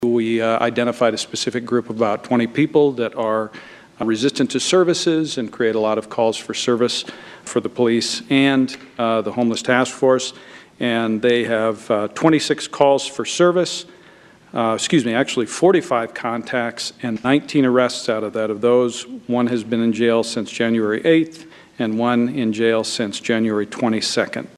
CITY POLICE CHIEF REX MUELLER DELIVERED HIS MOST RECENT REPORT ON THE OPERATIONS OF THE TASK FORCE ON THE HOMELESS TO THE SIOUX CITY COUNCIL THIS WEEK.